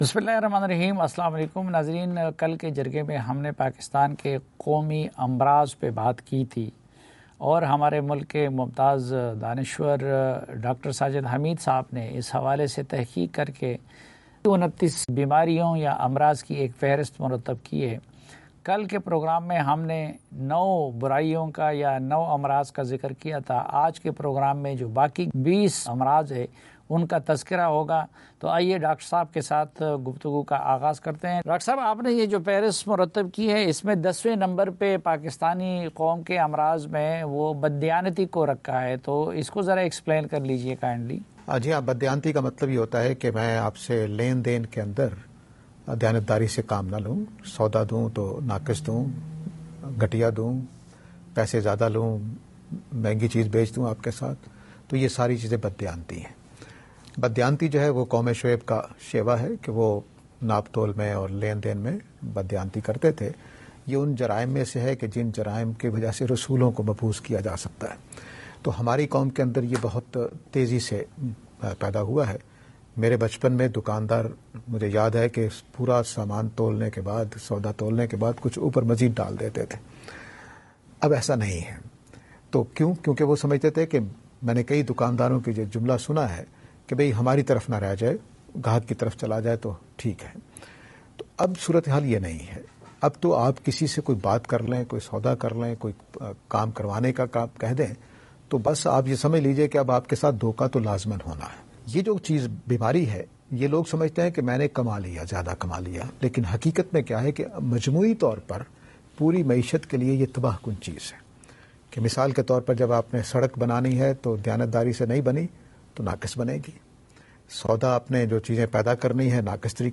خصوصی انٹرویو - جرگہ